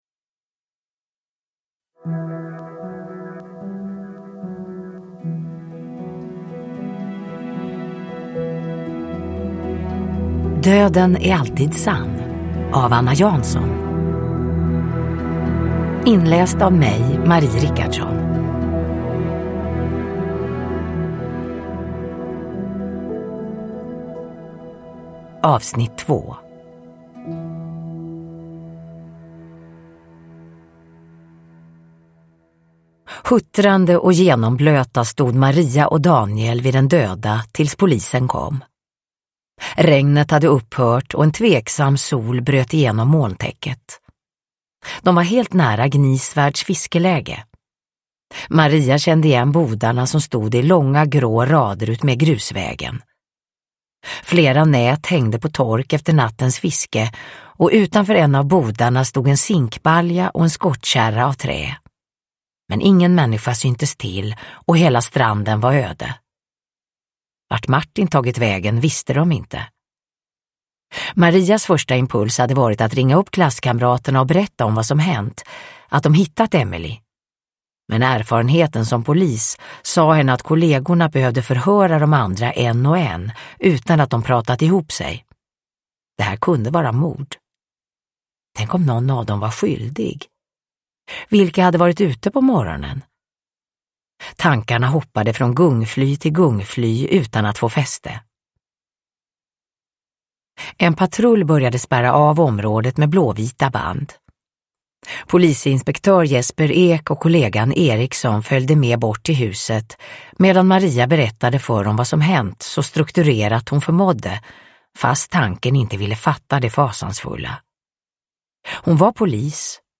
Döden är alltid sann - 2 – Ljudbok – Laddas ner
Uppläsare: Marie Richardson